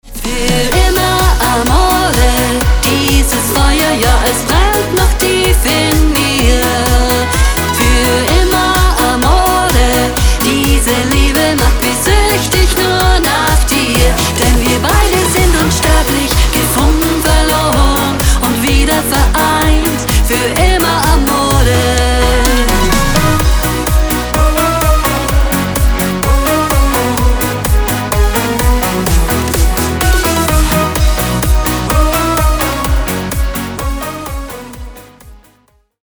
Genre.: Schlager